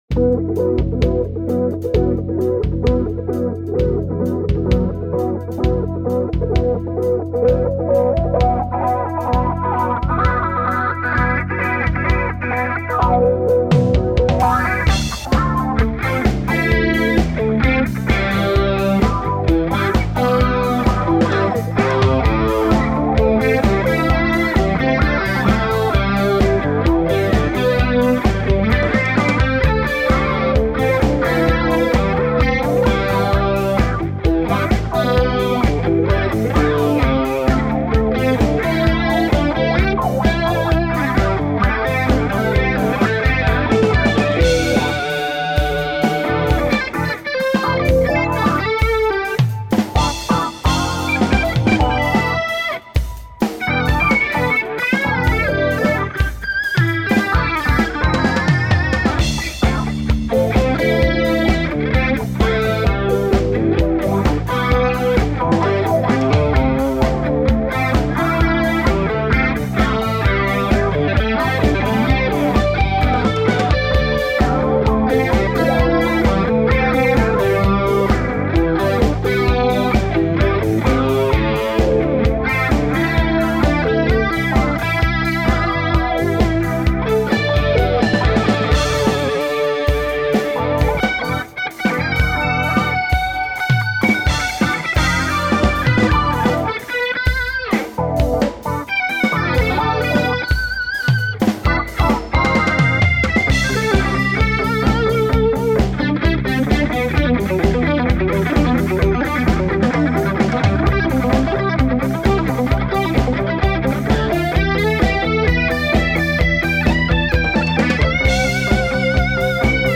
одна их композиций моего сольного проекта. записана в домашних условиях. на звуковой карте e-mu 1212m.